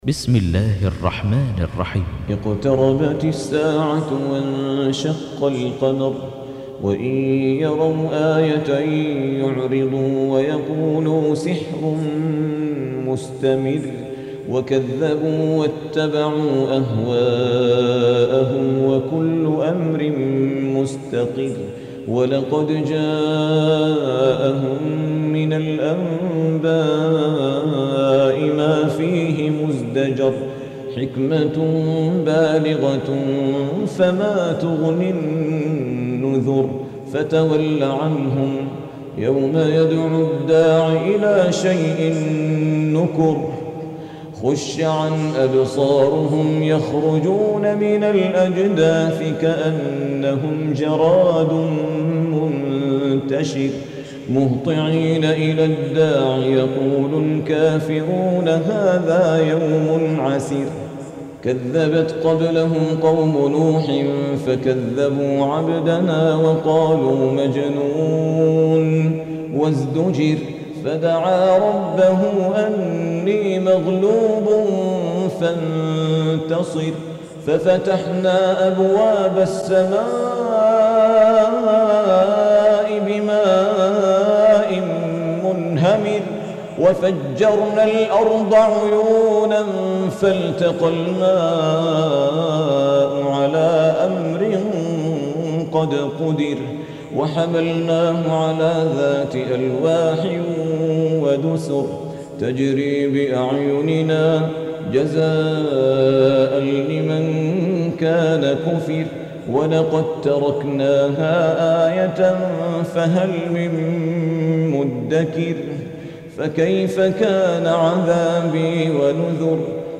54. Surah Al-Qamar سورة القمر Audio Quran Tarteel Recitation
Surah Repeating تكرار السورة Download Surah حمّل السورة Reciting Murattalah Audio for 54.